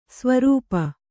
♪ svarūpa